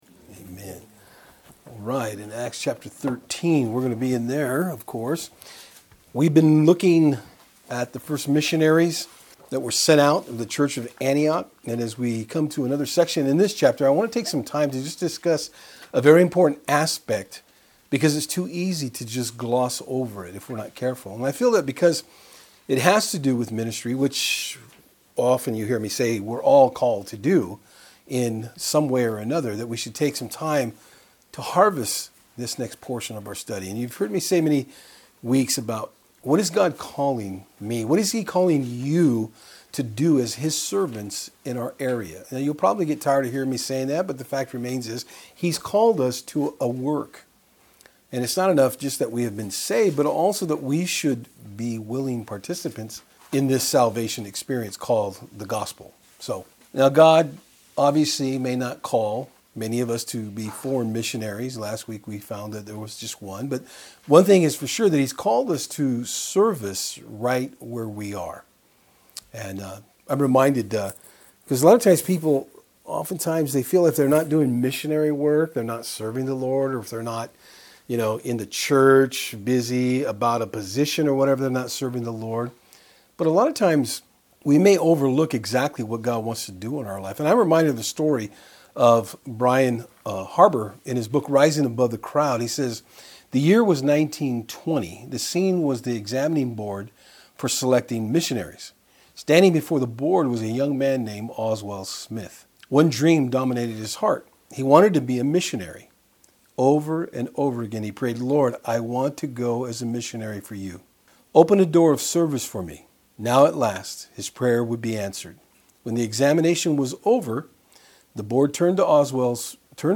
Service Type: Saturdays on Fort Hill